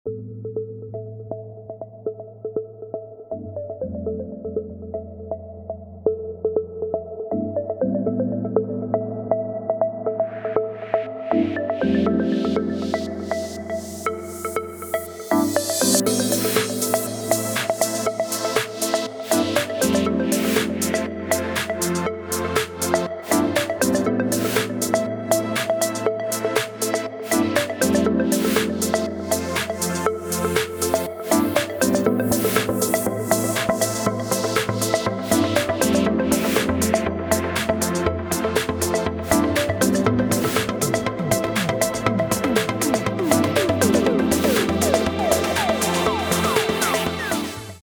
• Качество: 320, Stereo
красивые
Electronic
электронная музыка
спокойные
без слов
Downtempo
Lounge
Chill Out